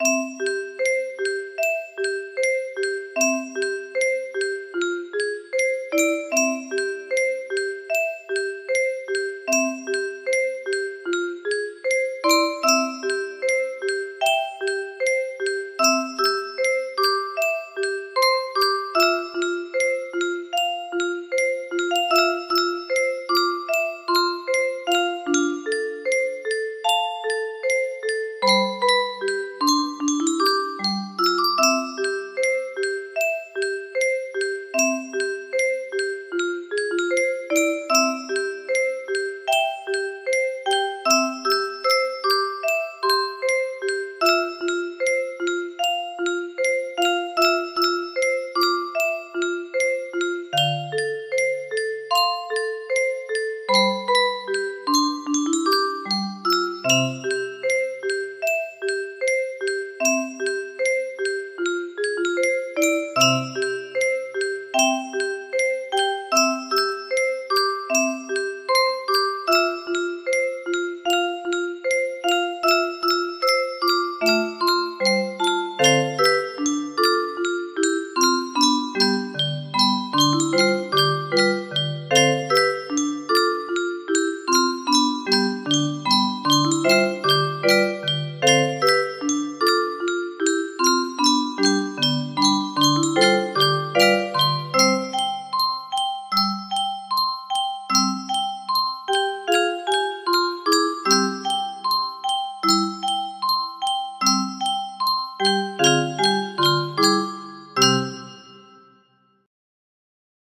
Fully playable on the 30-note music box.
Shortened version by omitting bridge and third verse.